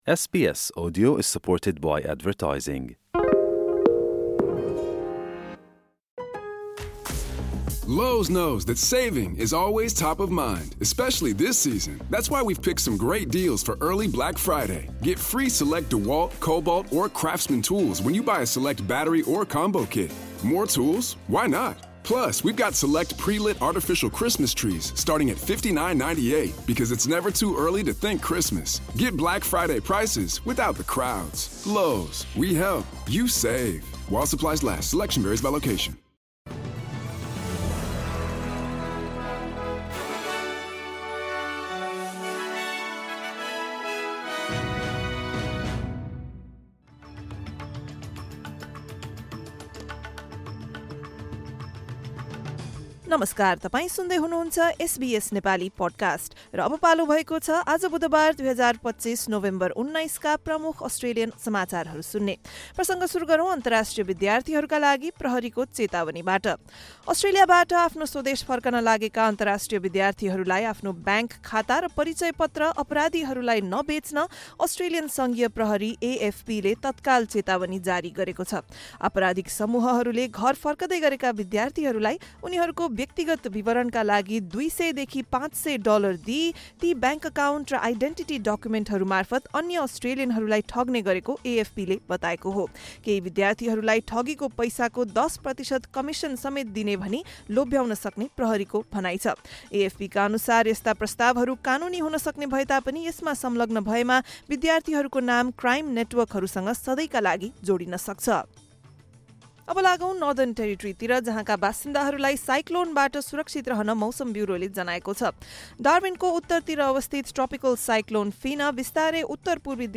एसबीएस नेपाली प्रमुख अस्ट्रेलियन समाचार: बुधवार, १९ नोभेम्बर २०२५